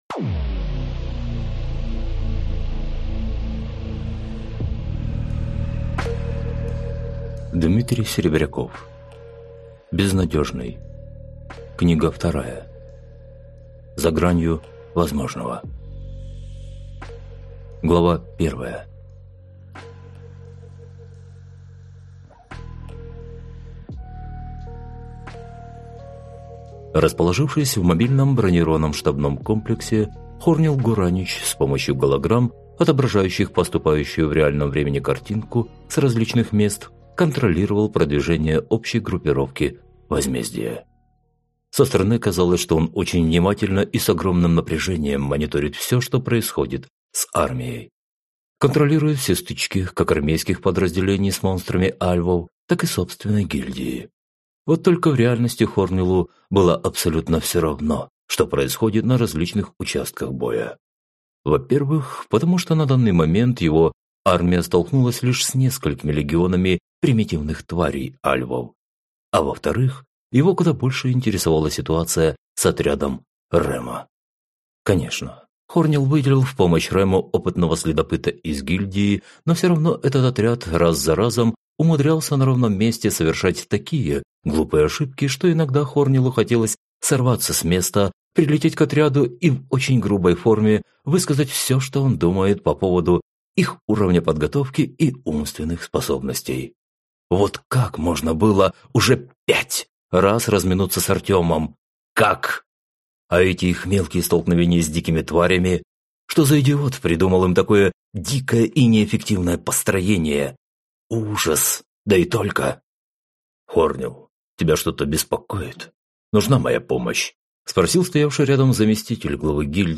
Аудиокнига За гранью возможного | Библиотека аудиокниг